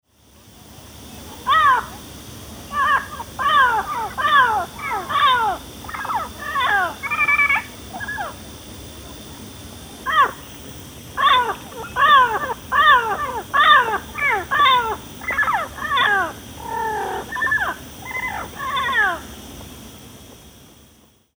Amazona aestiva
Inconfundível quando voa emitindo seu canto, geralmente aos pares, no início da manhã e no final da tarde.
Aprecie o canto do
Papagaio-verdadeiro
papagaioverdadeiro.mp3